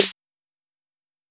Snare (The Morning).wav